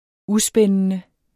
Udtale [ ˈuˌsbεnənə ]